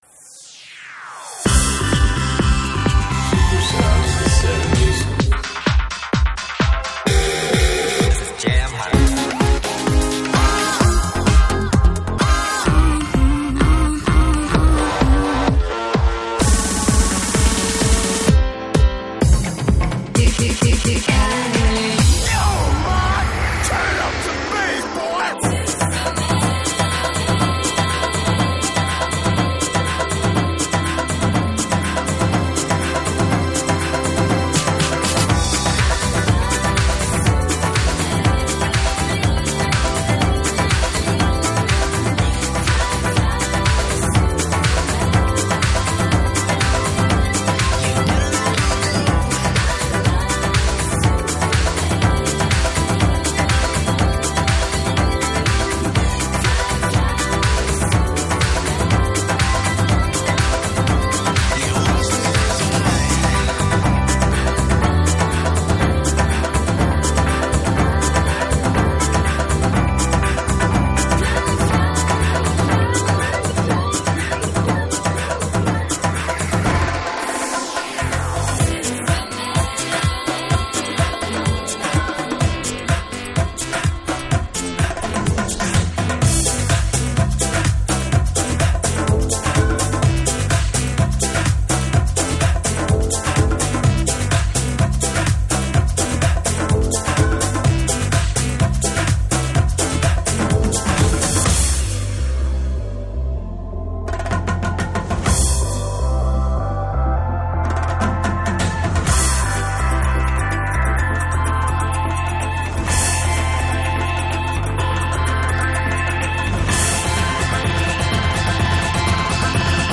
01: 000- 00 - Intro beats